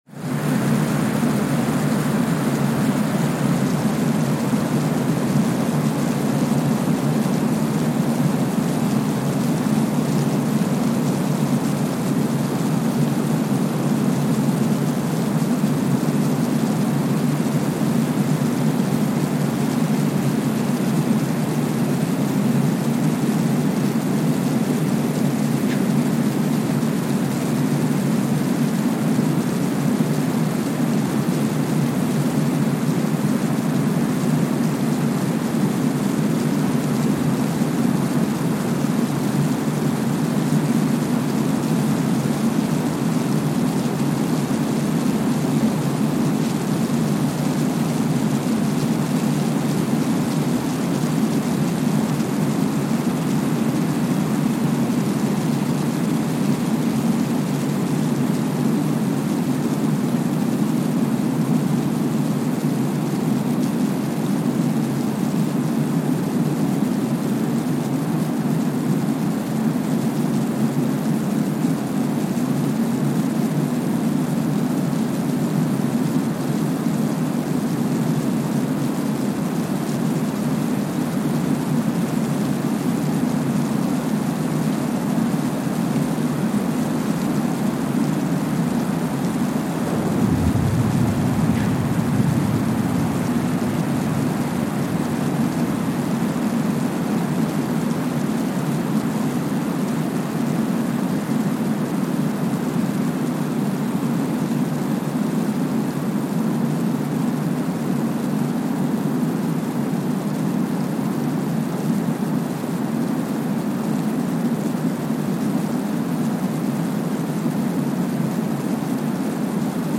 Kwajalein Atoll, Marshall Islands (seismic) archived on June 1, 2023
Sensor : Streckeisen STS-5A Seismometer
Speedup : ×1,000 (transposed up about 10 octaves)
Loop duration (audio) : 05:45 (stereo)
Gain correction : 25dB